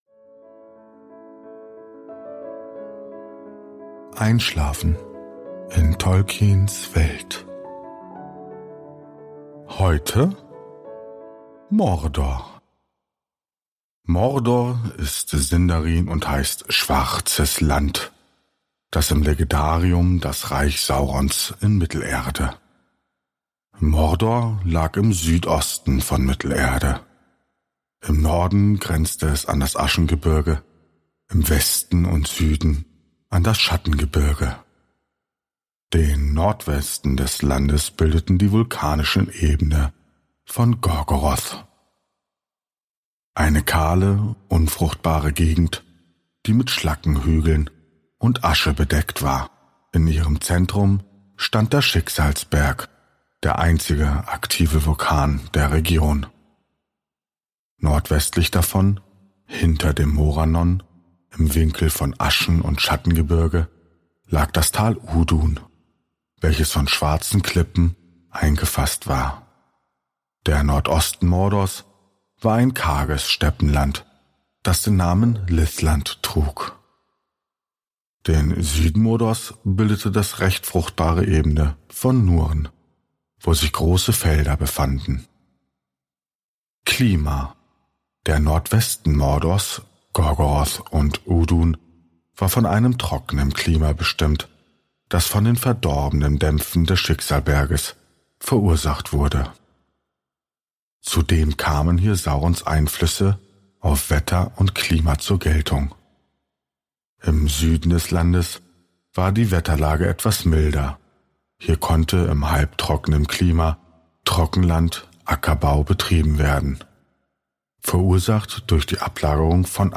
Bildung Silmarillion Hobbit Mittelerde Ardapedia Auenland Productions Tolkien Herr der Ringe Einschlafen Einschlafhilfe Tolkien-stimmen Einschlafpodcast